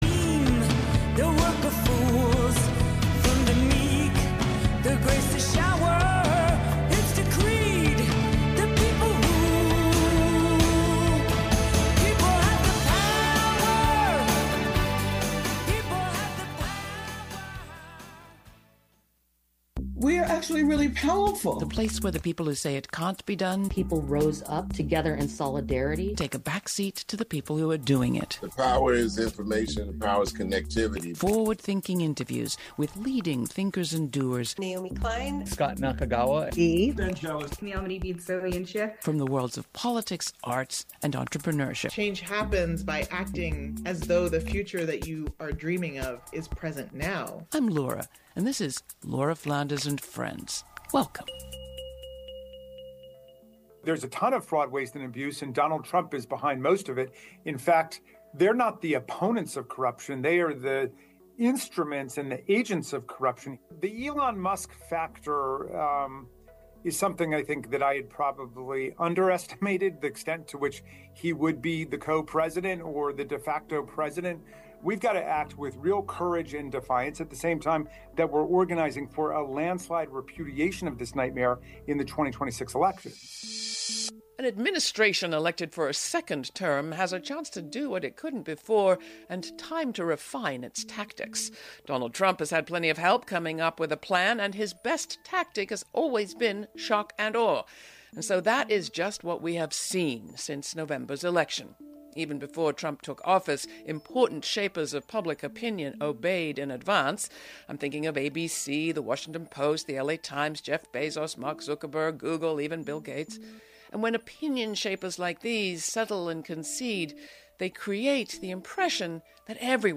Laura Flanders and Friends is a TV and radio program that seeks to raise radical spirits by interviewing forward-thinking people who have real experience of shifting power, from the few to the many, in the worlds of arts, entrepreneurship and politics.